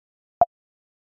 Звуковые эффекты iMac и MacBook